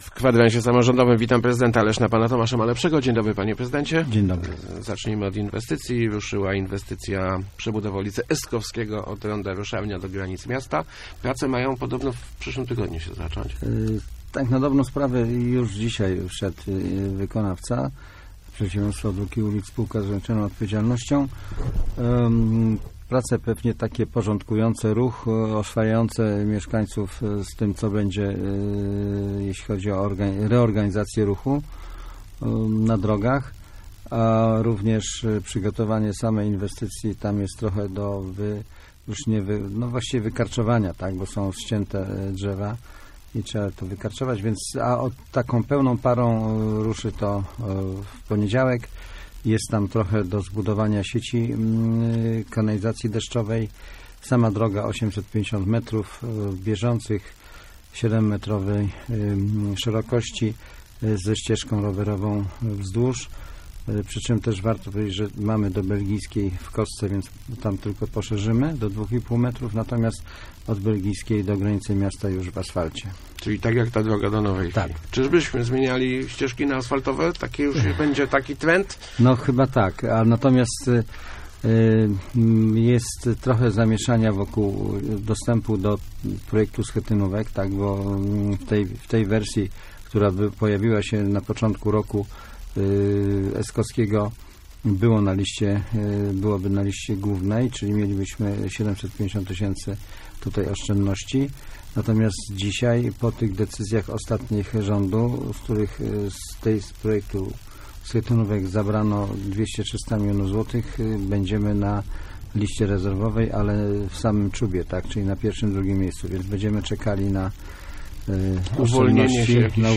Gościem Kwadransa był prezydent Tomasz Malepszy. ...